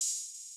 {OpenHat} lose.wav